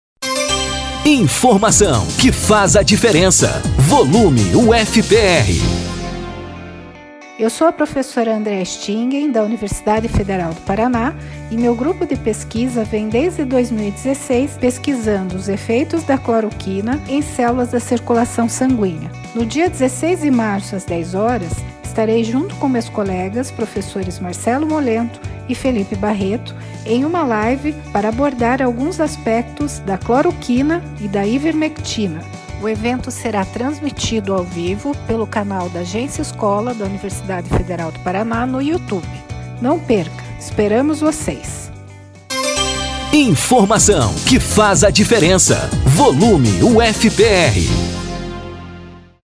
Ouça abaixo um boletim do Volume UFPR, que tem produção da Agência Escola UFPR e parceria da UniFM, sobre o Divulga Ciência AE: